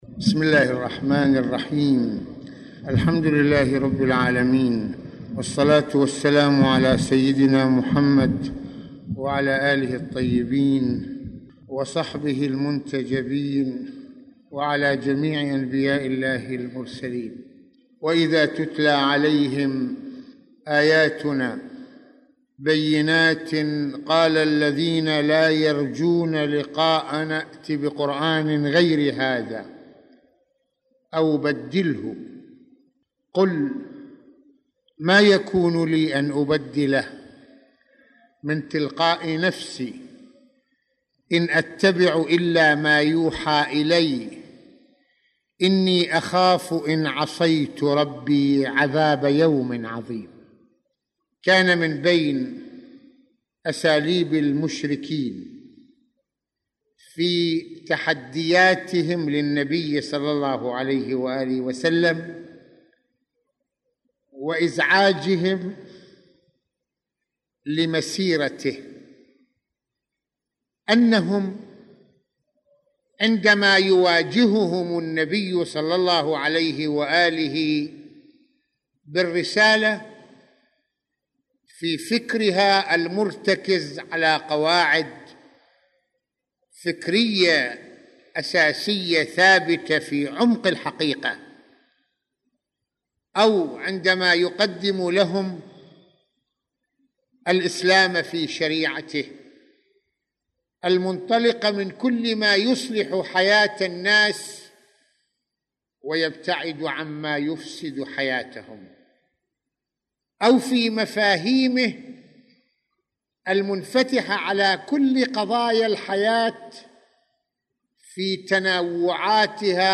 Mohadara_Tefseer_Ayyat_4.mp3